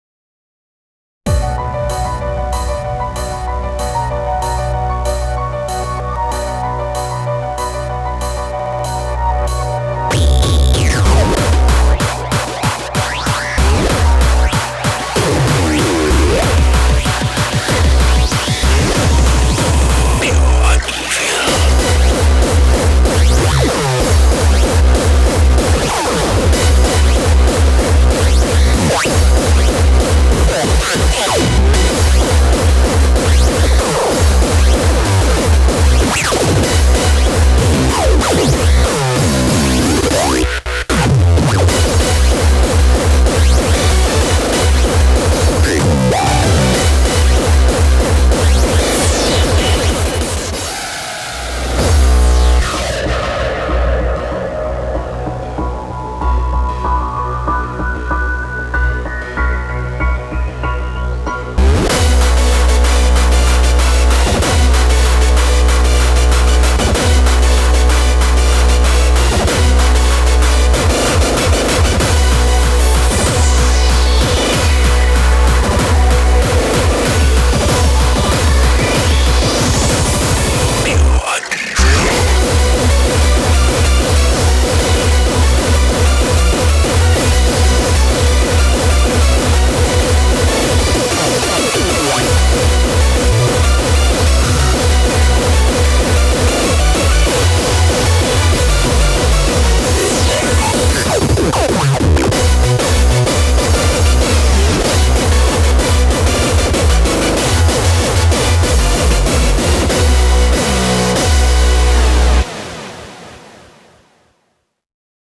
BPM190--1
Audio QualityPerfect (High Quality)